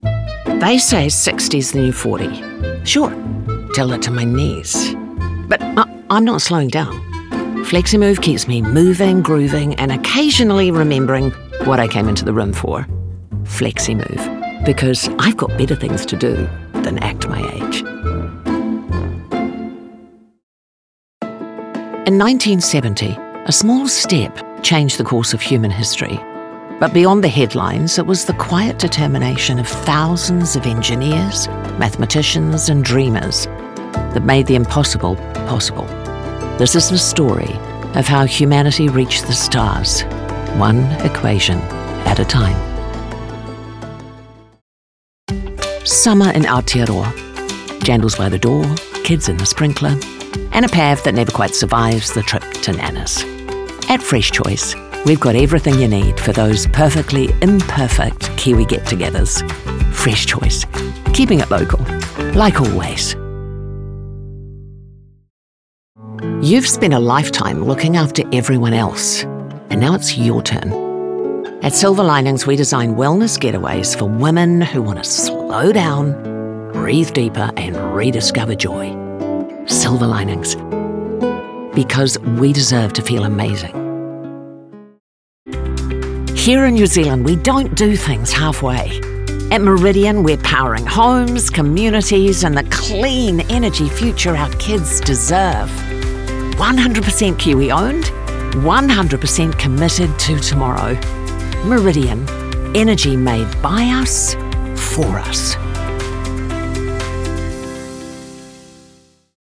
Demo
Mature Adult
non-broadcast level home studio